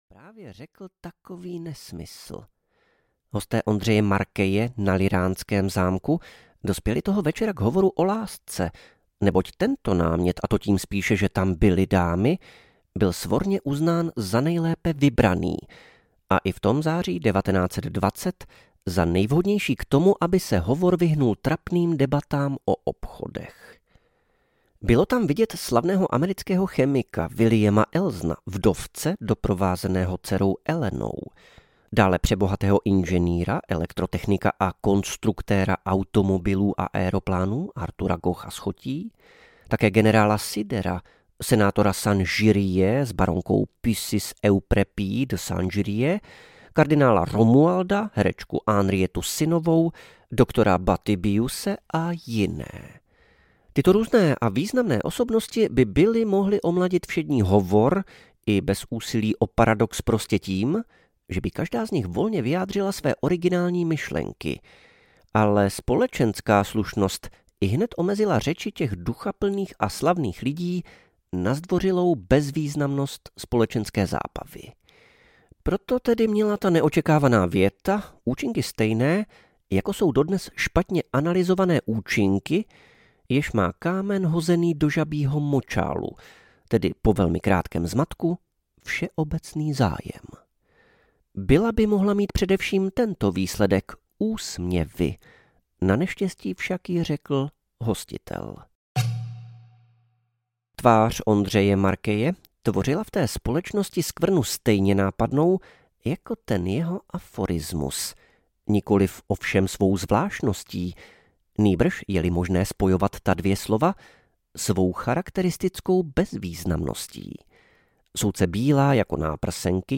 Nadsamec audiokniha
Ukázka z knihy